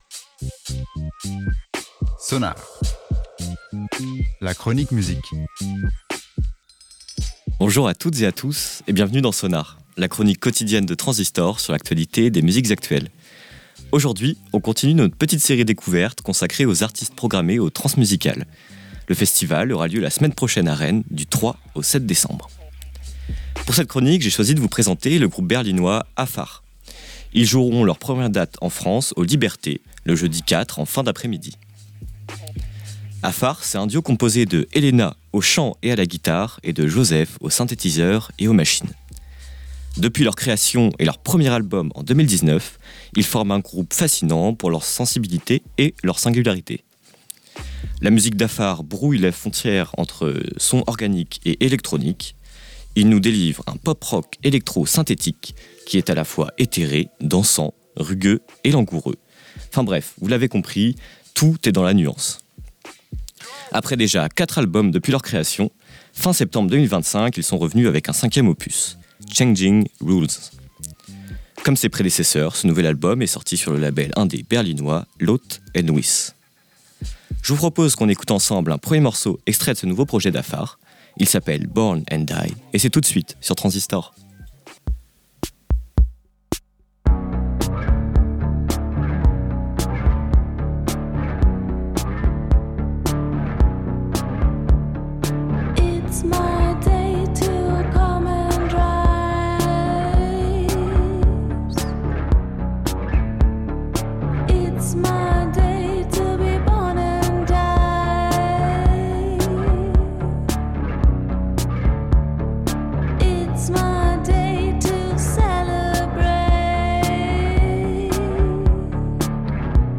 guitare
synthétiseurs